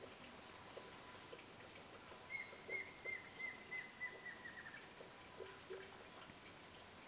Choca Amarilla (Dysithamnus mentalis)
Nombre en inglés: Plain Antvireo
Localidad o área protegida: Bio Reserva Karadya
Condición: Silvestre
Certeza: Fotografiada, Vocalización Grabada
choca-amarilla.mp3